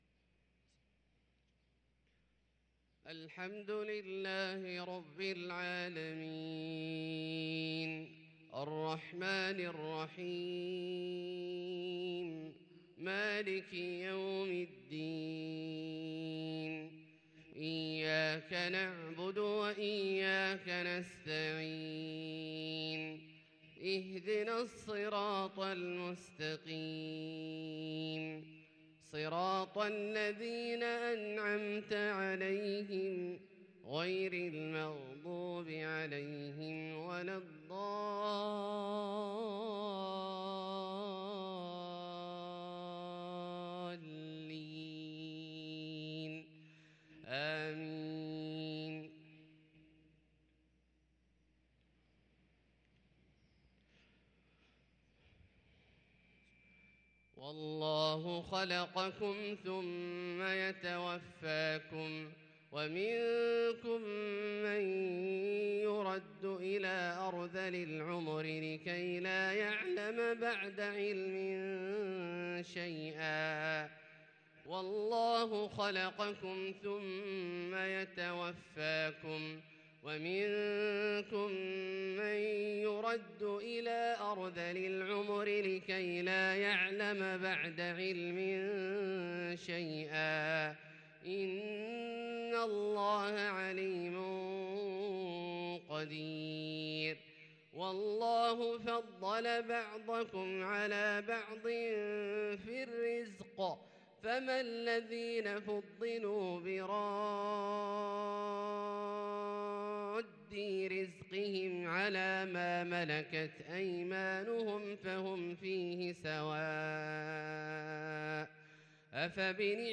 صلاة الفجر للقارئ عبدالله الجهني 23 جمادي الأول 1444 هـ
تِلَاوَات الْحَرَمَيْن .